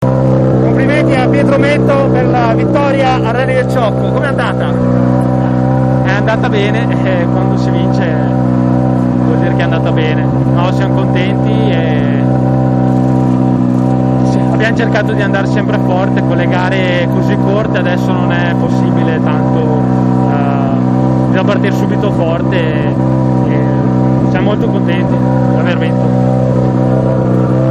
Interviste di fine rally